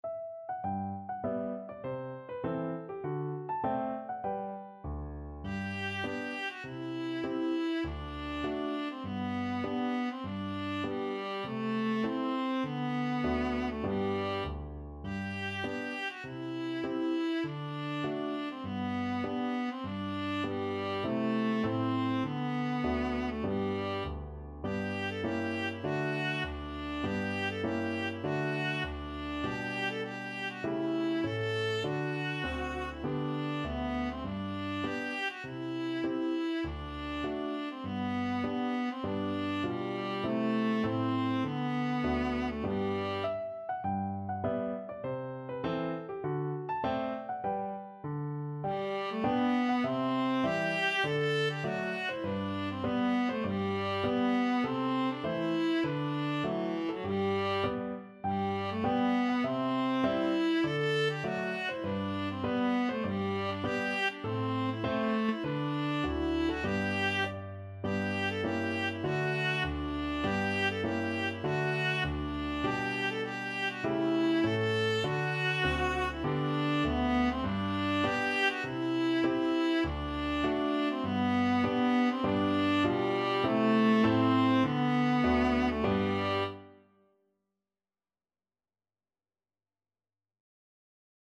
Viola
Traditional Music of unknown author.
G major (Sounding Pitch) (View more G major Music for Viola )
Moderato
E4-A5